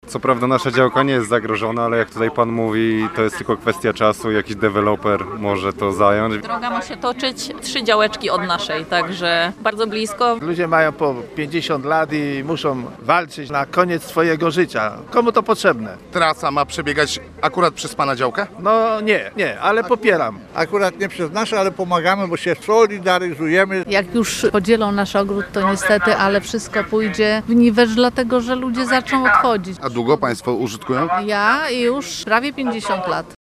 Ponad stu działkowców pikietowało dziś przed gdyńskim ratuszem.
dzialkowcy-krotko.mp3